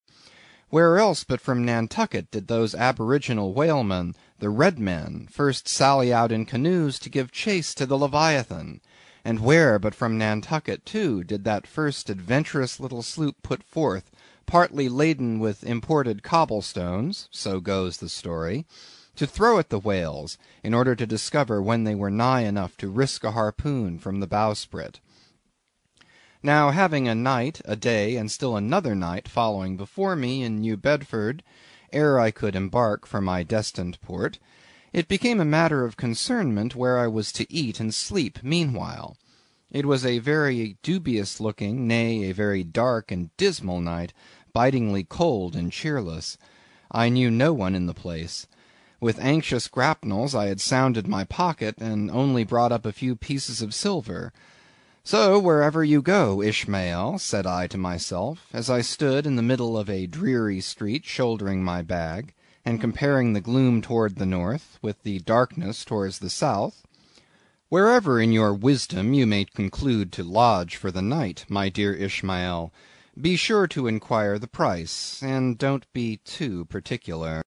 英语听书《白鲸记》第181期 听力文件下载—在线英语听力室